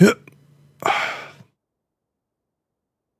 Grunt3.ogg